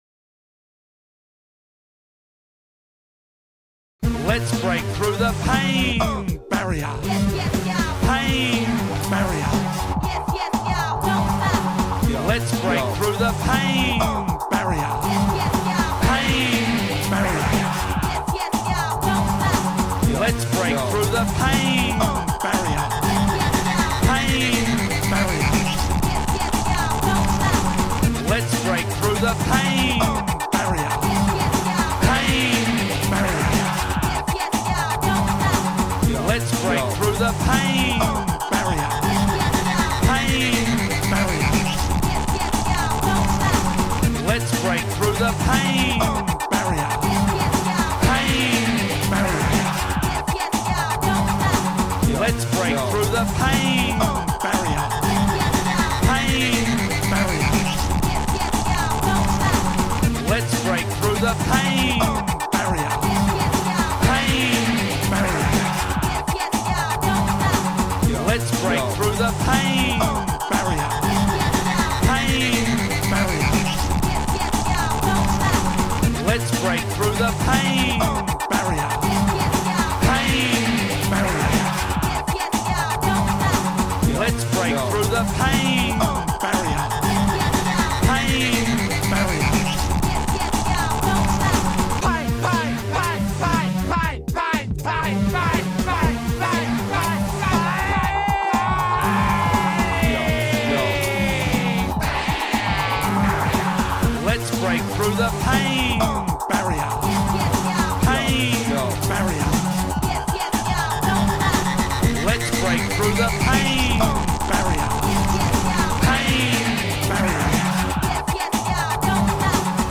all-funked-up, party-down, Jihad-ready remix